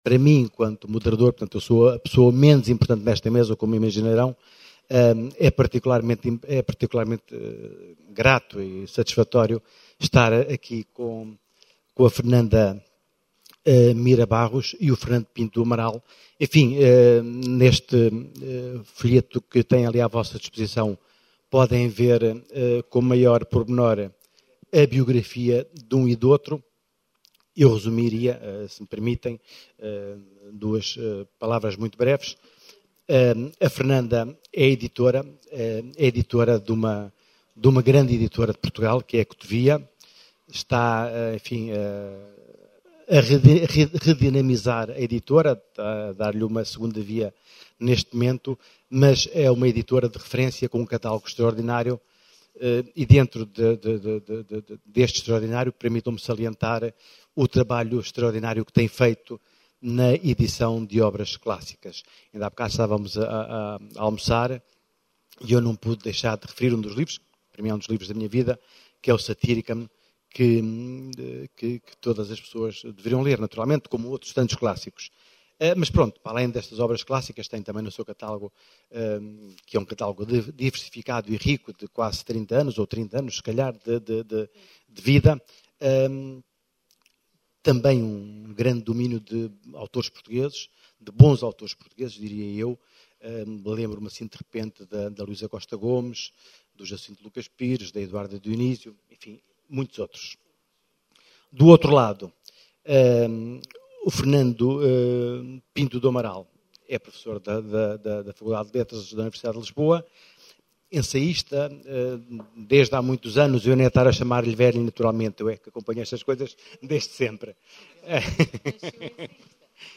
Uma conversa amplamente agitada pela discussão saudável dos conceitos. A palavra e o discurso são a essência de um livro, quando tudo depende da interpretação do leitor para que o livro se assuma no seu nascimento.